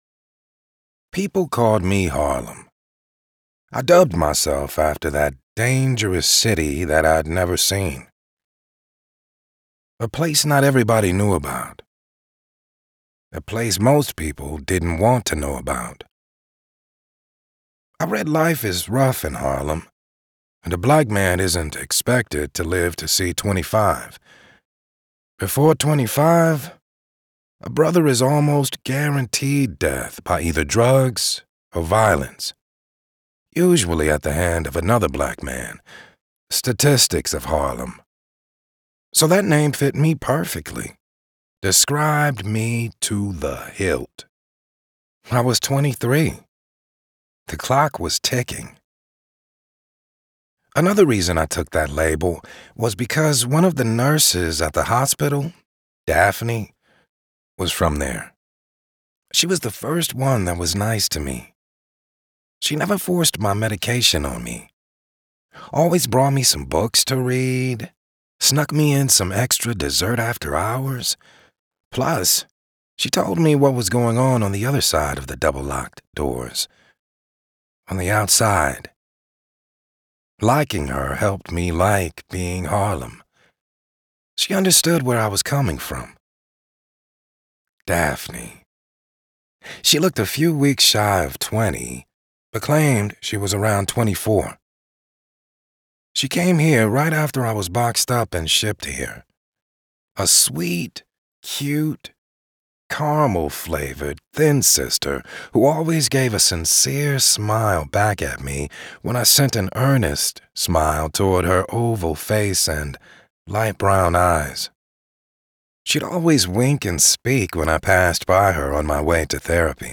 Available for the first time as an audiobook, New York Times bestselling author Eric Jerome Dickey’s thrilling short story Harlem